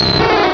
Cri de Minidraco dans Pokémon Rubis et Saphir.